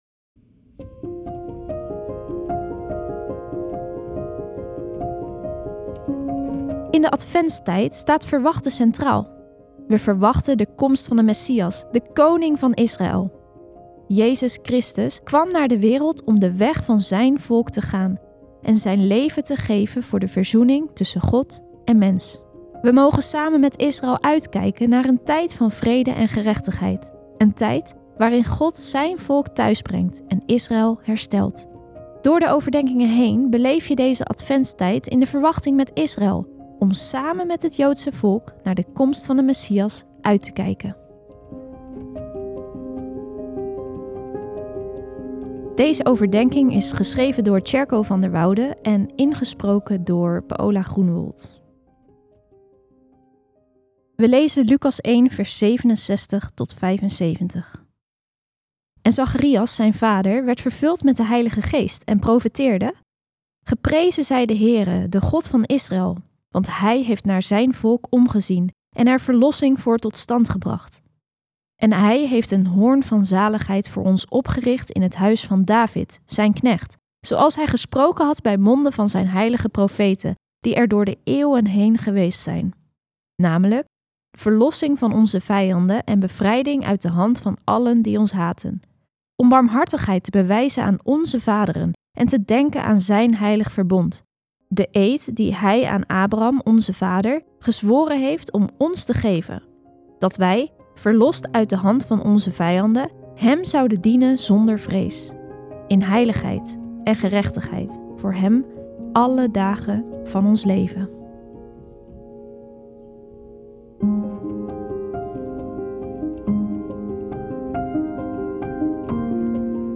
Christenen voor Israël Overdenking